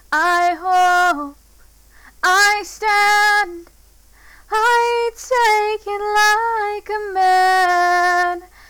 It’s not clipped (as in hitting 0 dB maximum volume).